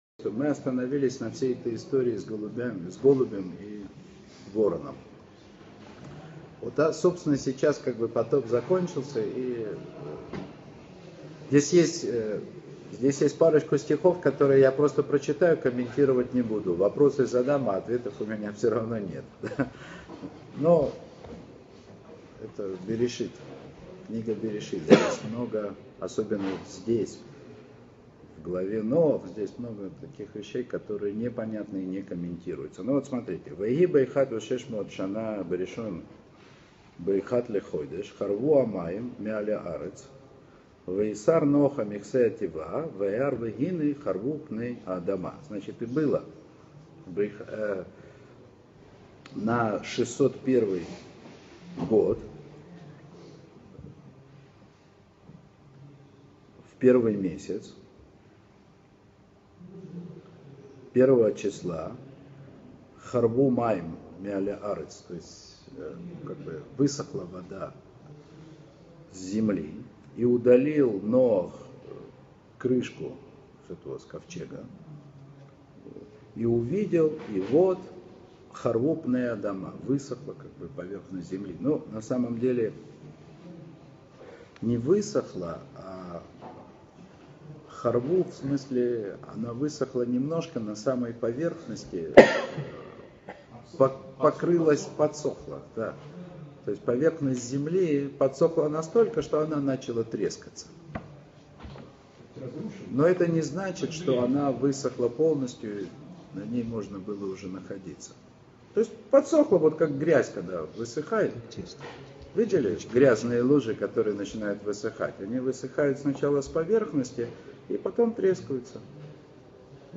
Уроки по книге Берейшит.